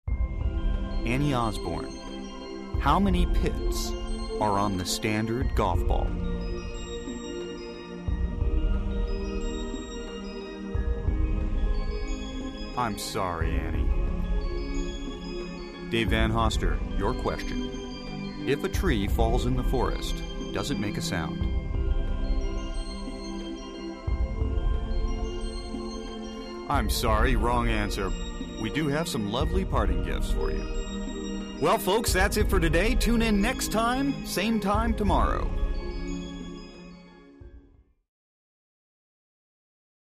Television; Game Show Questions With Music And Announcer. From Close.